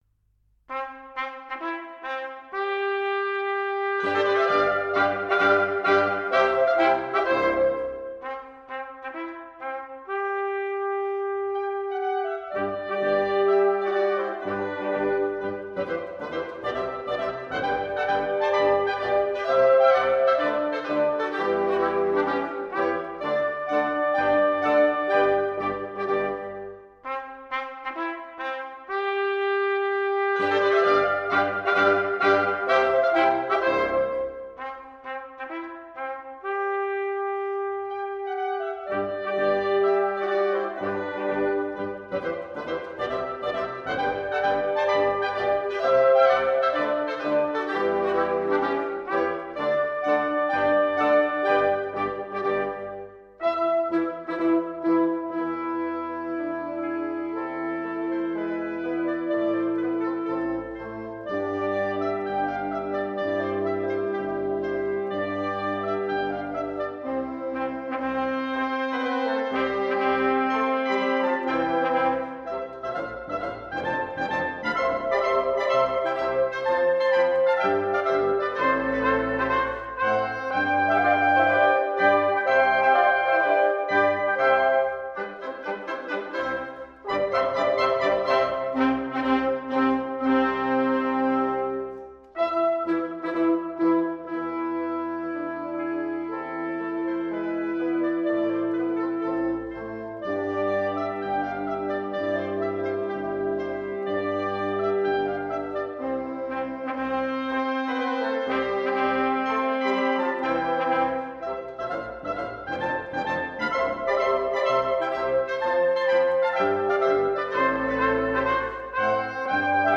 1. C大调进行曲，为小喇叭、各二支双簧管、竖笛、法国号、低音管与倍低音管